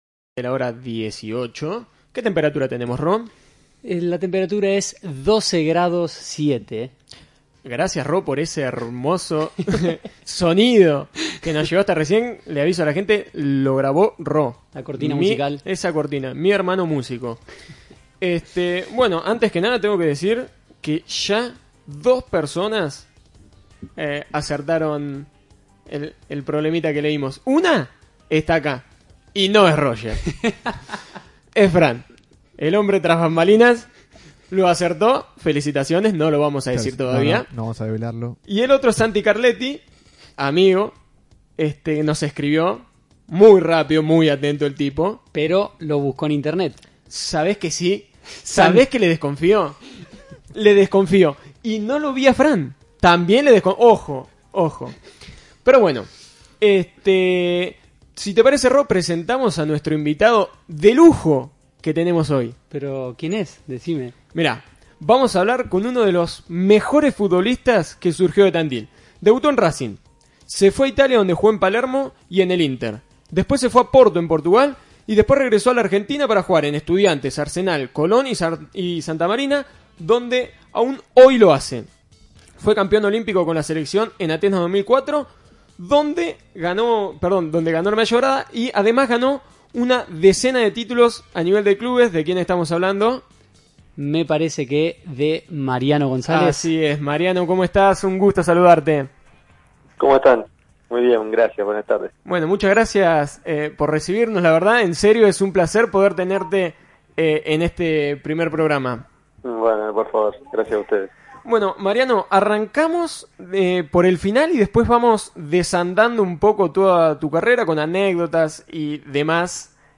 Escuchá la entrevista completa y accedé al Instagram del programa para conocer mucho más.